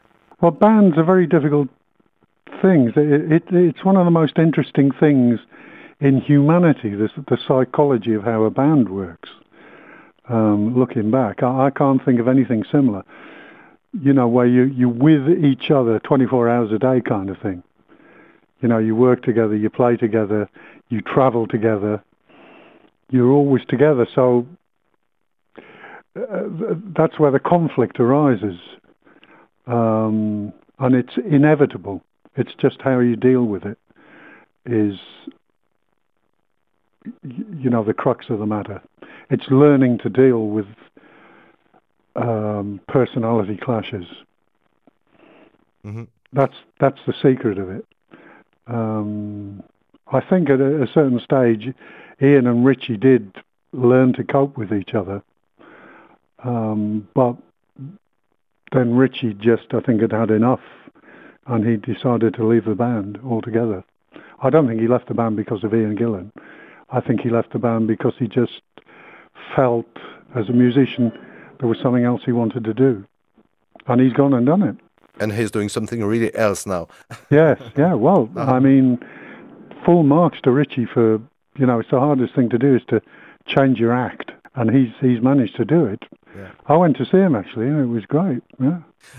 Don Airey, clavier de Deep Purple